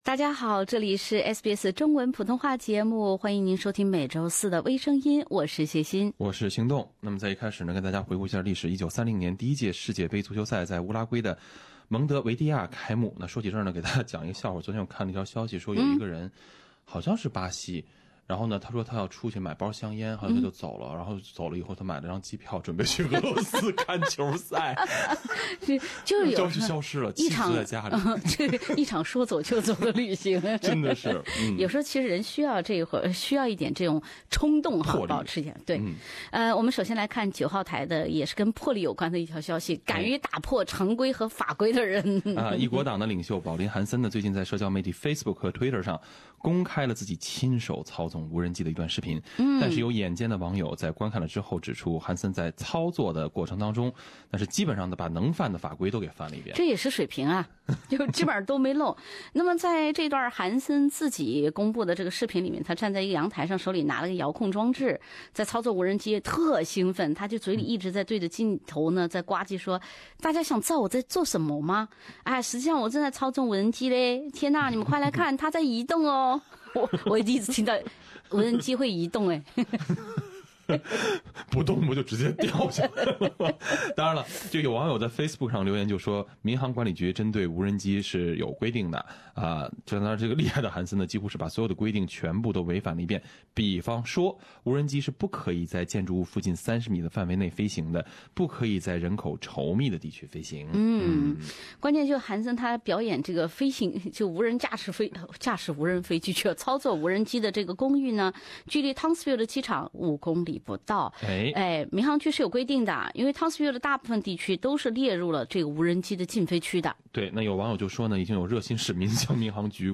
另类轻松的播报方式，深入浅出的辛辣点评，包罗万象的最新资讯，倾听全球微声音。